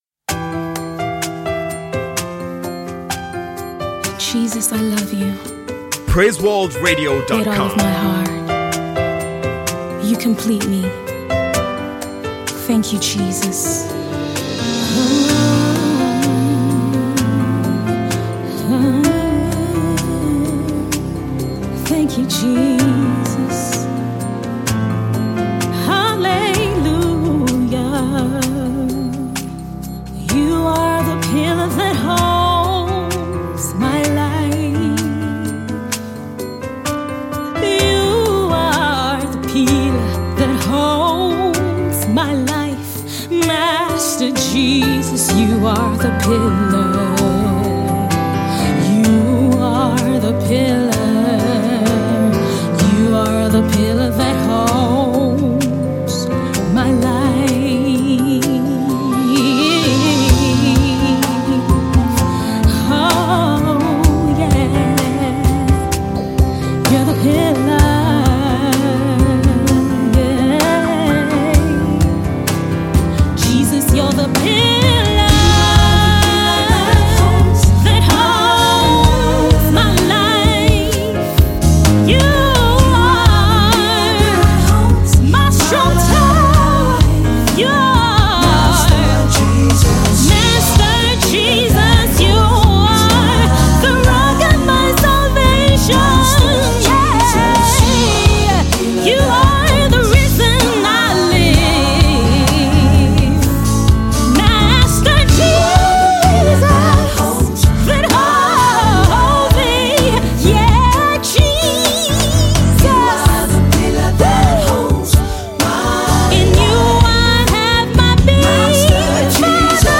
gospel song